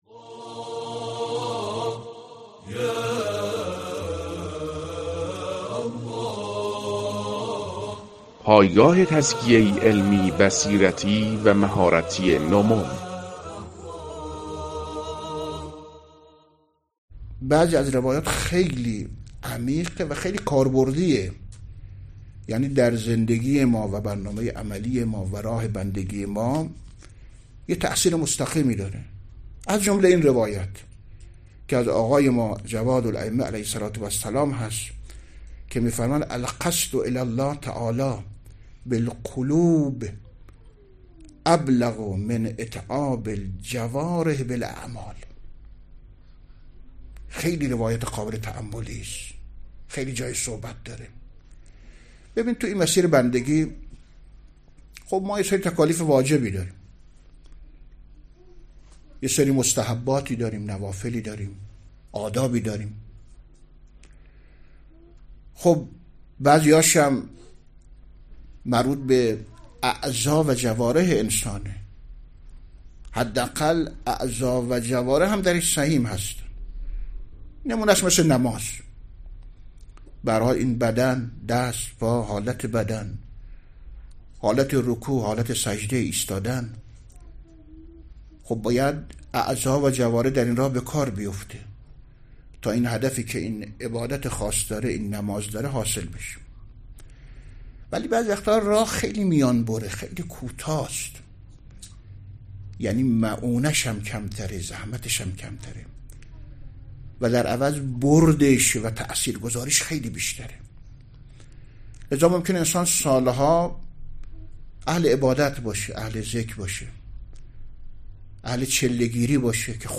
مواعظ گوناگون و مطالب و نکاتی که در قالب صوت هستند و مختصر و مفید می‌باشند و پند و اندرز می‌دهند، در این بخش مطرح می‌شود.